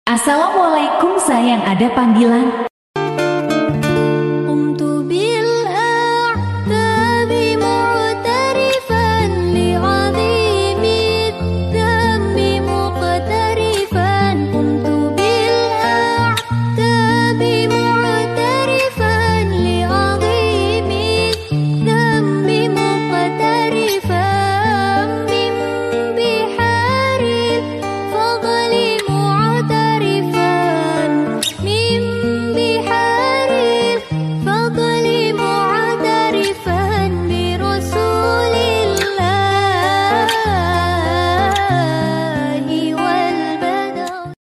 Kategori: Nada dering
Suara khas dan lucu bikin HP kamu beda dari yang lain...
nada-dering-assalamualaikum-sayang-ada-panggilan-viral-tiktok-id-www_tiengdong_com.mp3